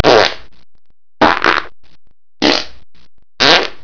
FUN WAVs
farts.wav